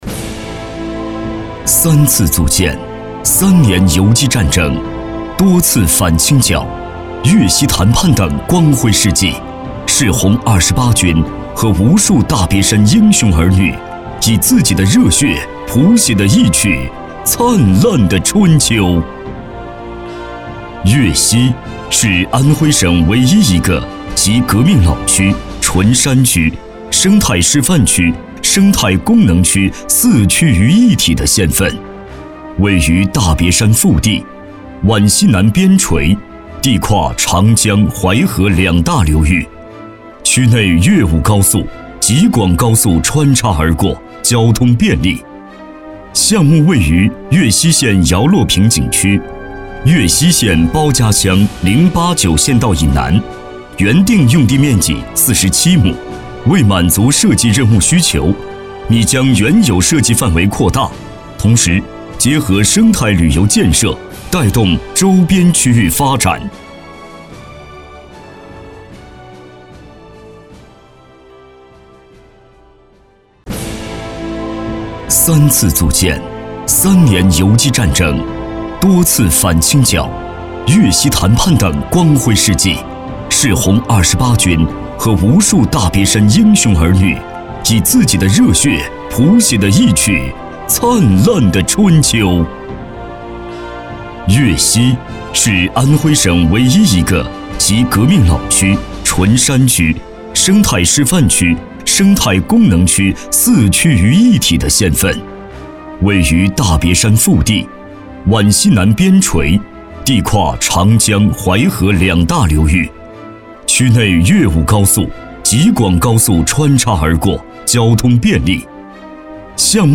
职业配音员全职配音员浑厚大气
• 男S353 国语 男声 专题片 粤西解说-城乡解说-沉稳抒情 大气浑厚磁性|沉稳